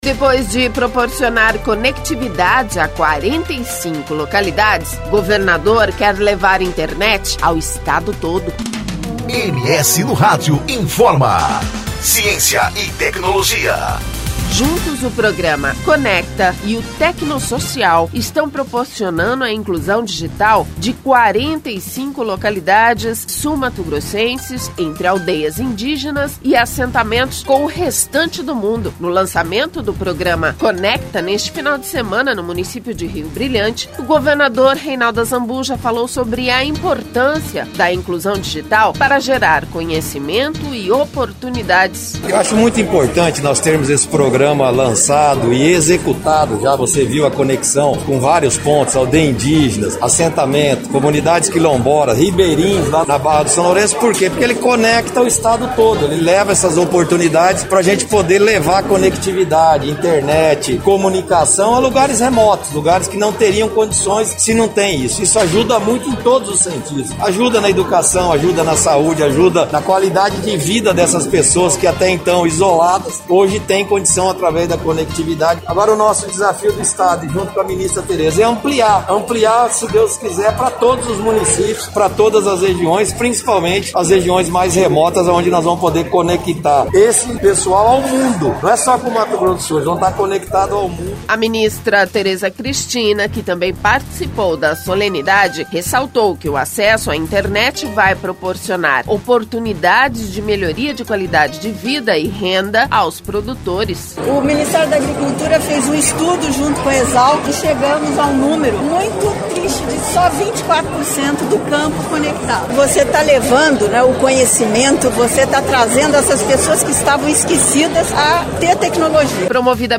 No lançamento do Programa Conecta, neste final de semana, em Rio Brilhante, o governador Reinaldo Azambuja falou sobre a importância da inclusão digital para gerar conhecimento e oportunidades.
A ministra Tereza Cristina que também participou da solenidade ressaltou que o acesso à internet vai proporcionar oportunidades de melhoria da qualidade de vida e renda aos produtores.